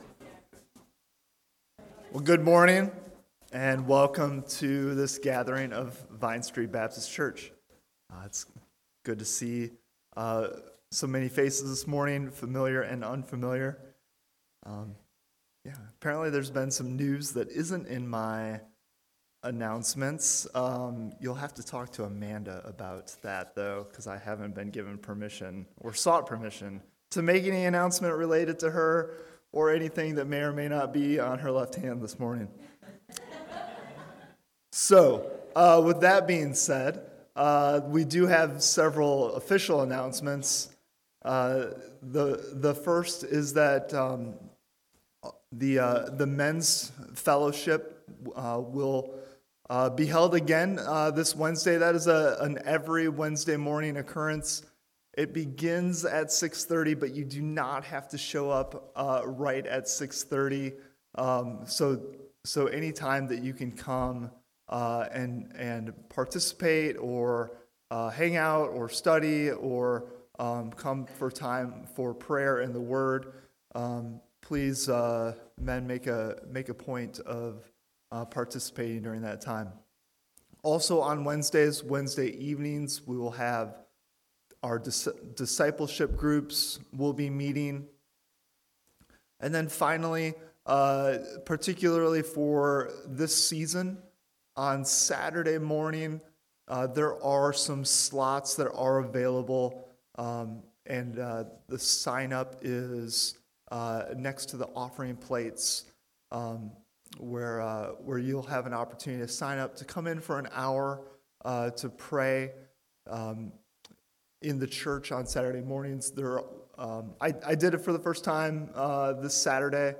March 20 Worship Audio – Full Service